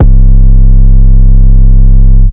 808 5 {C} [ rattlesnake ].wav